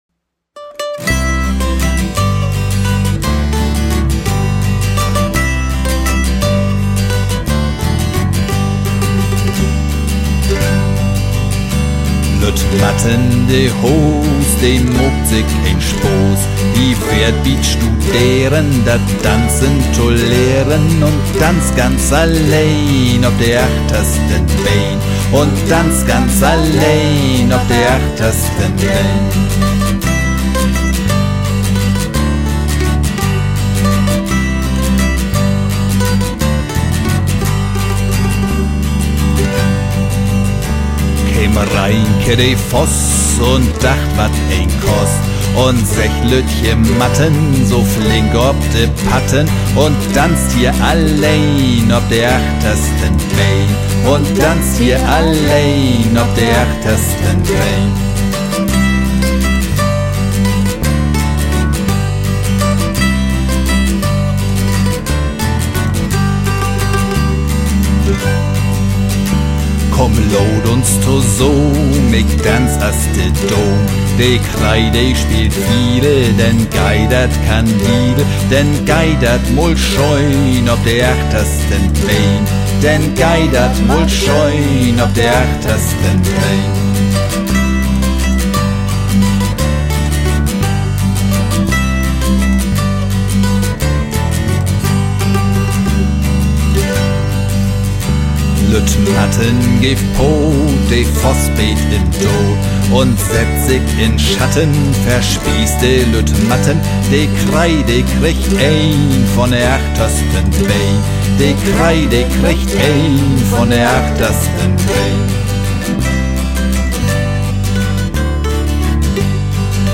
aufgenommen im Oktober 2019 im Tonstudio